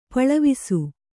♪ paḷavisu